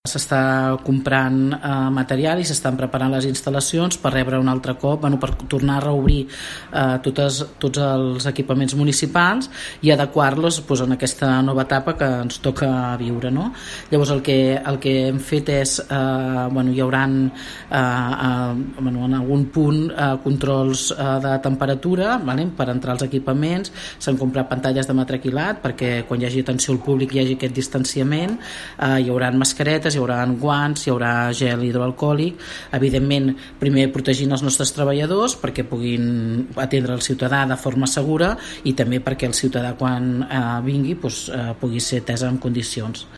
Susanna Pla és 1a Tinent d’alcalde de l’Ajuntament de Palafolls.